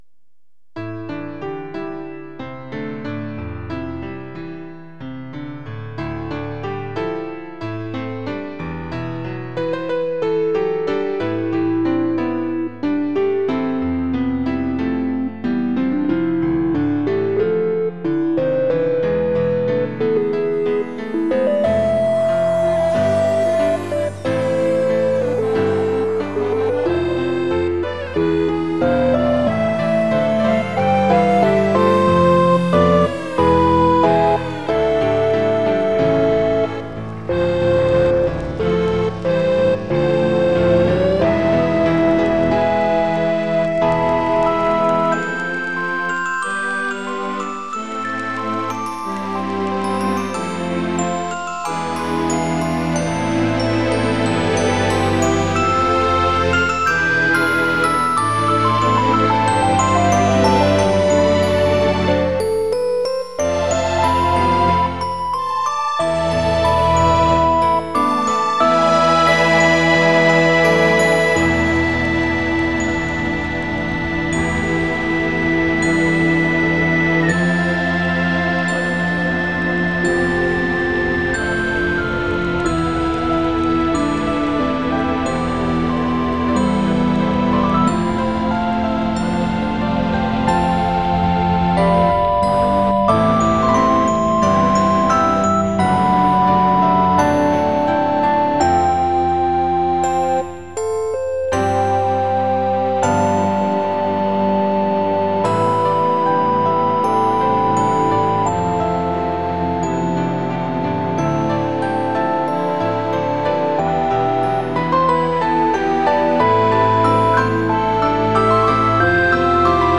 淡々としながら、何かに躓くようなモヤモヤ感が伝われば嬉しい。